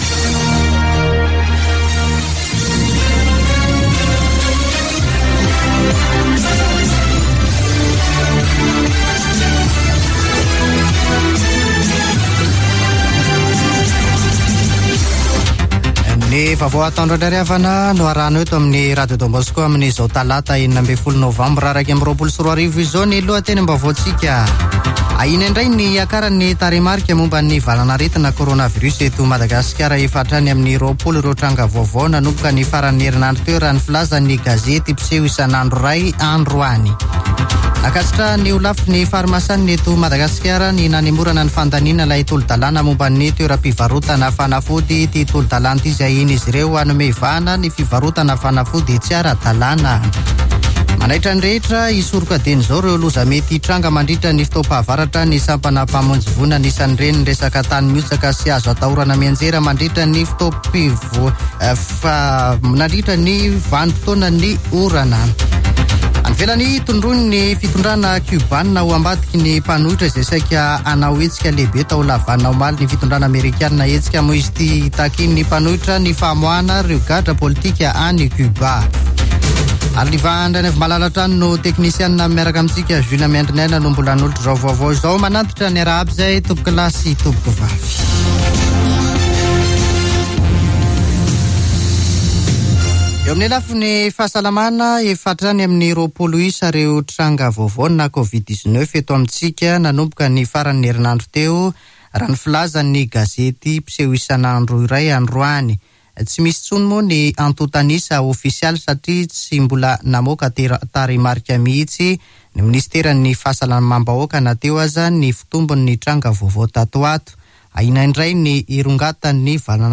[Vaovao antoandro] Talata 16 novambra 2021